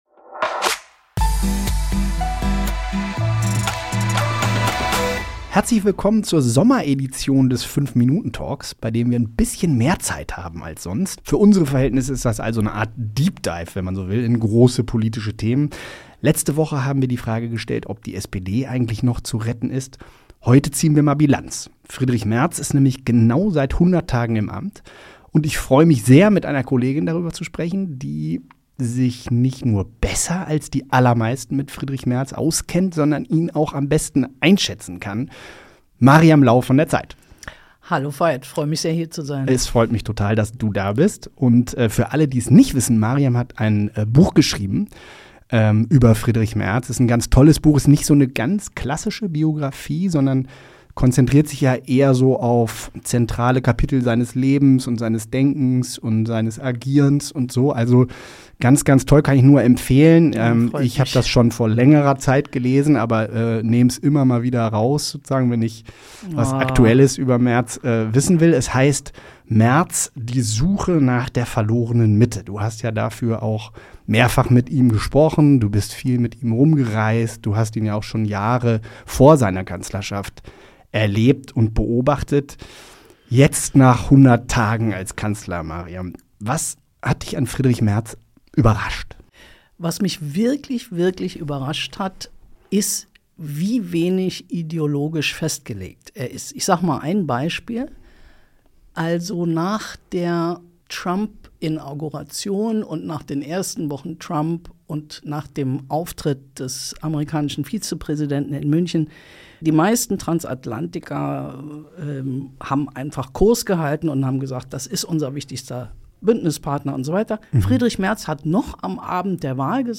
Im Talk